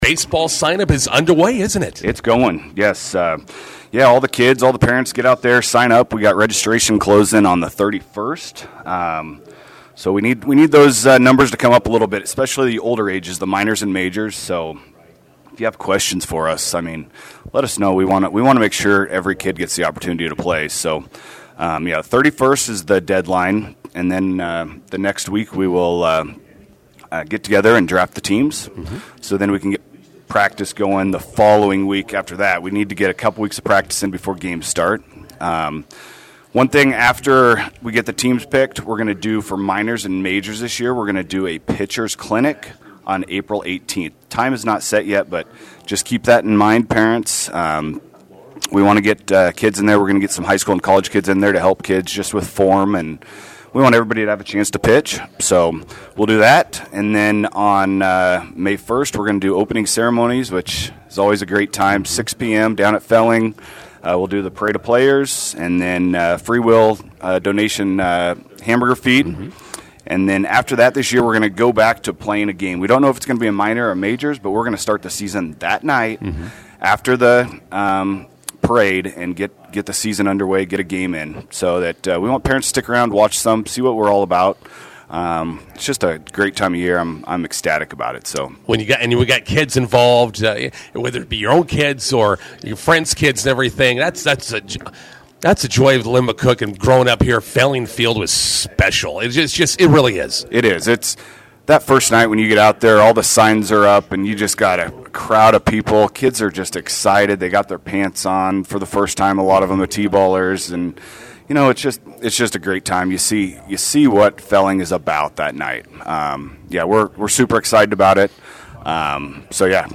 INTERVIEW: McCook Youth Baseball Association signup continues.